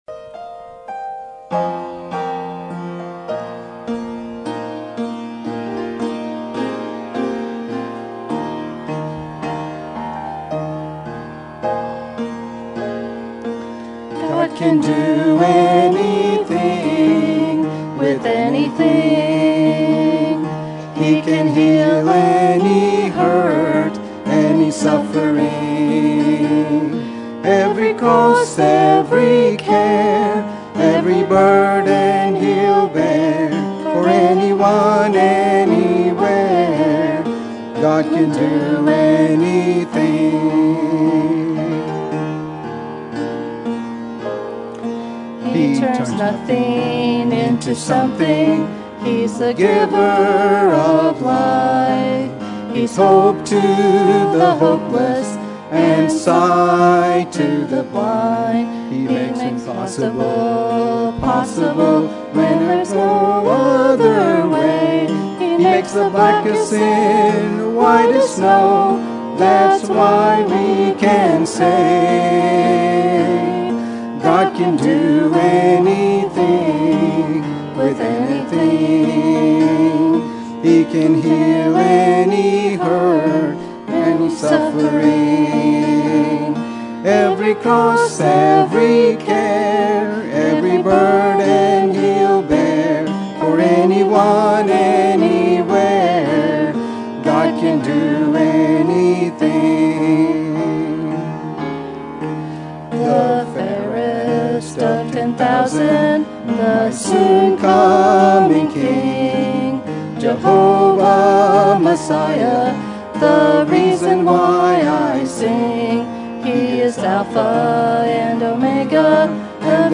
Sermon Topic: Famous Last Words Sermon Type: Series Sermon Audio: Sermon download: Download (28.28 MB) Sermon Tags: Kings Elijah Last Words Elisha